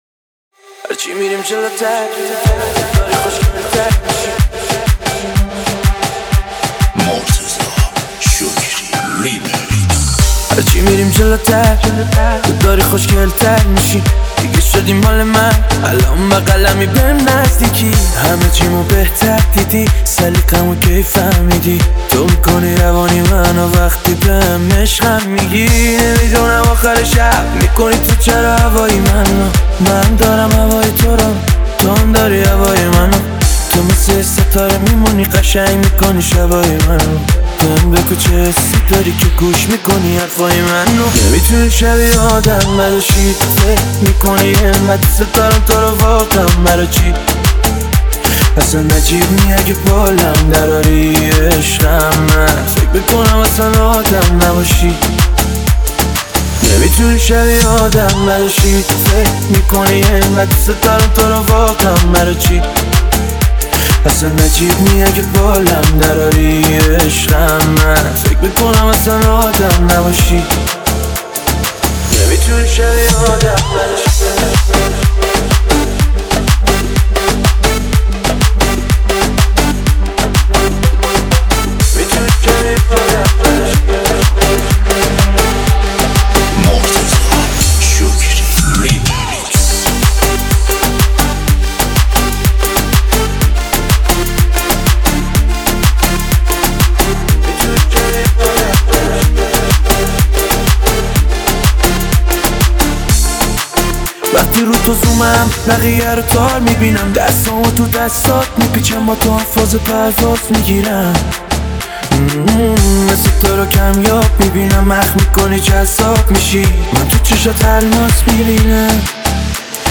ریمیکس تند بیس دار
ریمیکس سوم